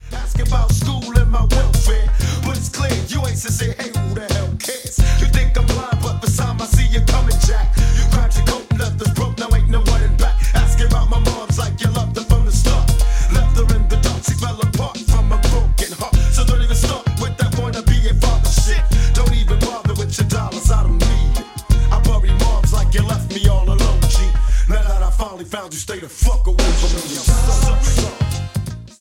28 second low-bitrate audio sample of the song
This is a sound sample from a commercial recording.